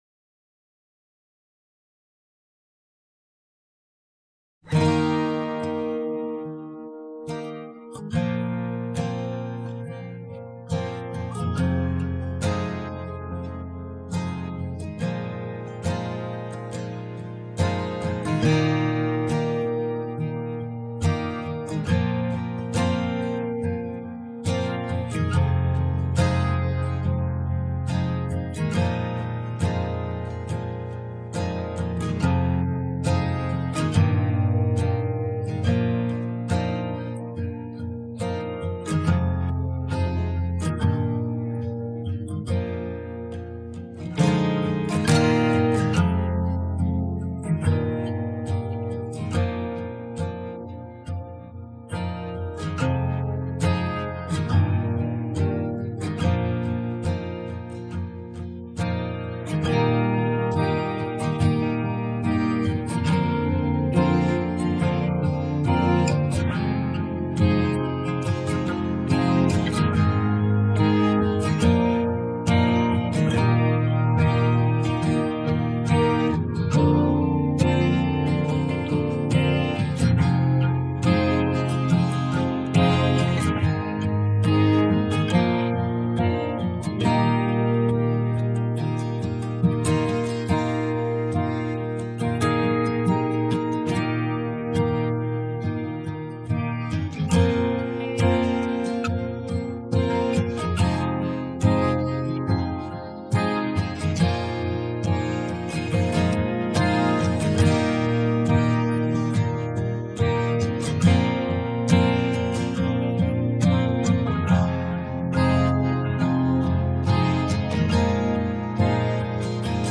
exclusive guitar mp3 track